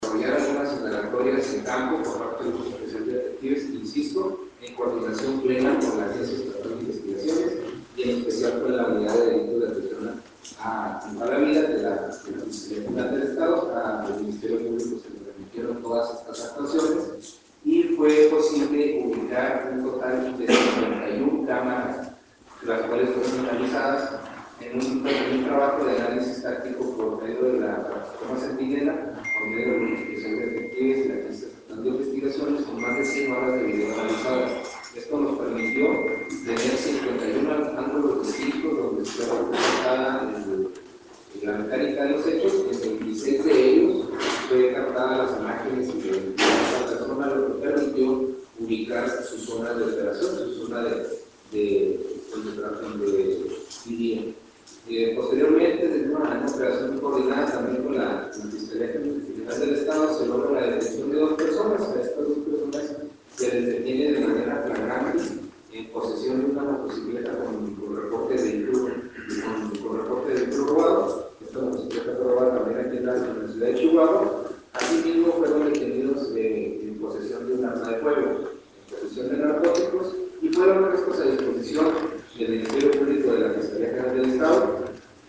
AUDIO: LUIS AGUIRRE, TITULAR DE LA SUBSECRETARÍA DE ESTADO MAYOR DE LA SECRETARÍA DE  SEGURIDAD PÚBLICA DEL ESTADO (SSPE)